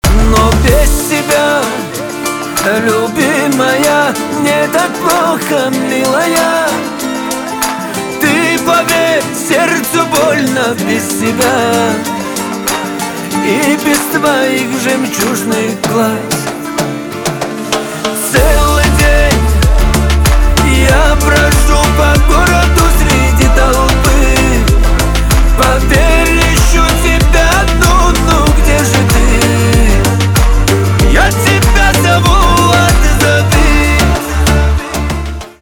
кавказские